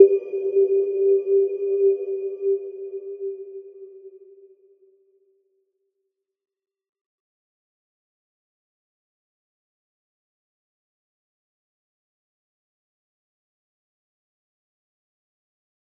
Little-Pluck-G4-mf.wav